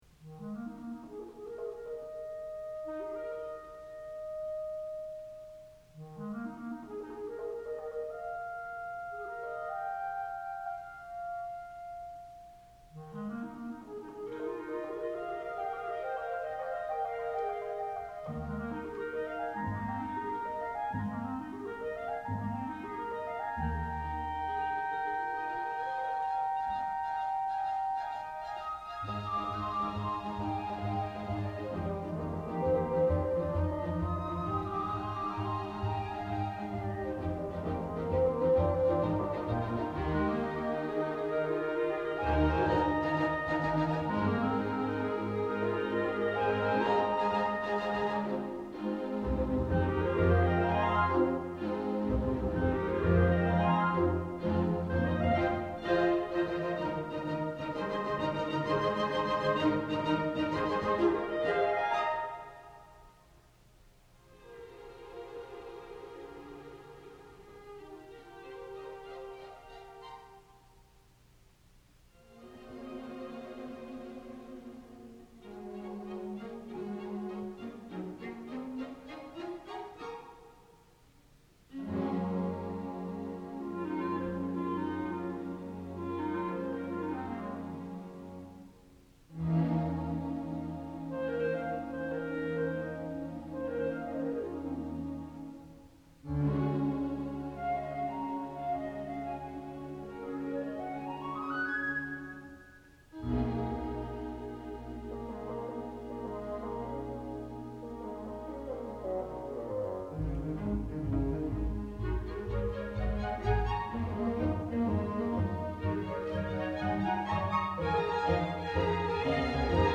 for Chamber Orchestra (1997)
is a fanciful movement inspired by the poem